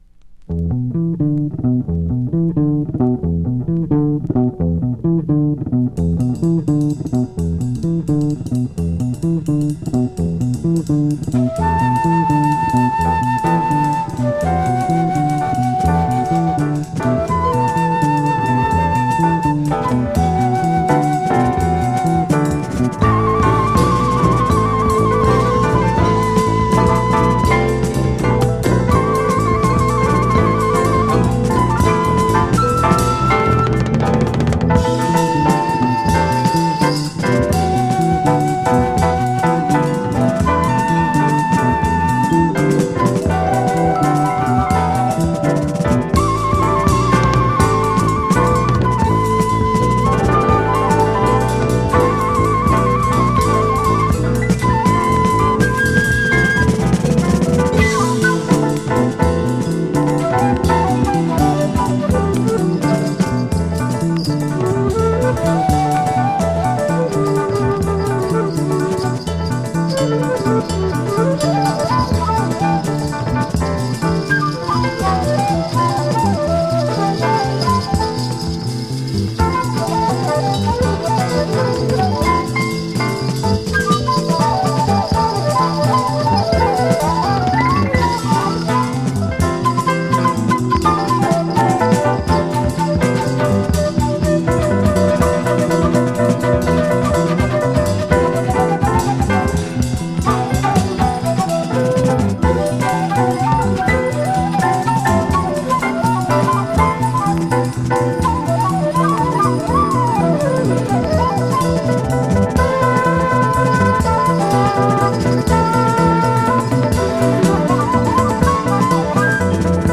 ディープで美しい